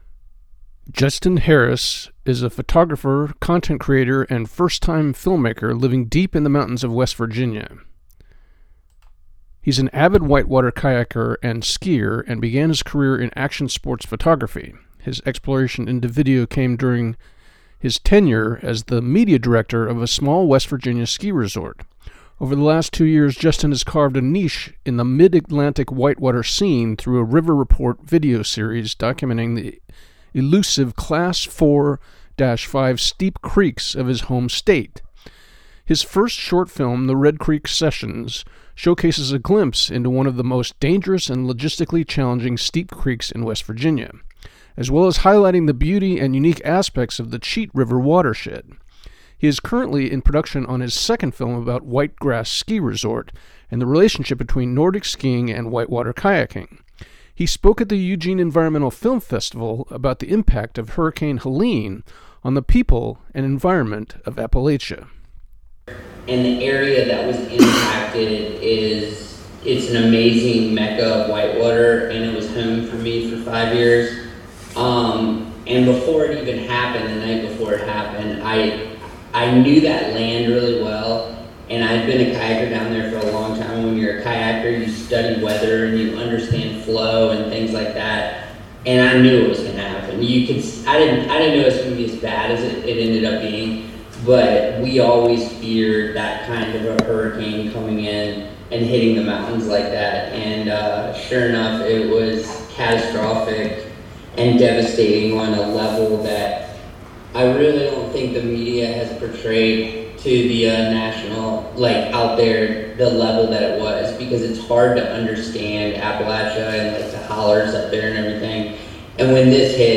He spoke at the Eugene Environmental Film Festival about the impact of hurricane Helene on the people and environment of Appalachia